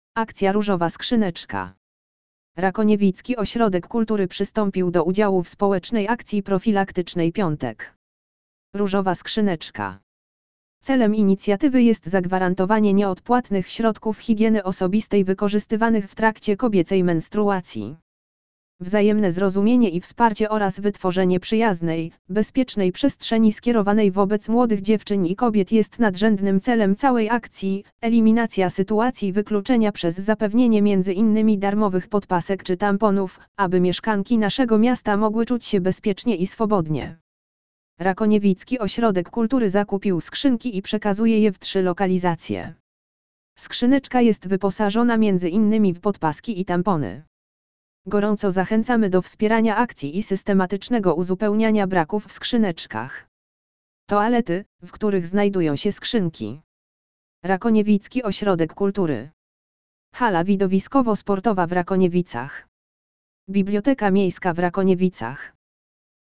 lektor_audio_akcja_rozowa_skrzyneczka_!.mp3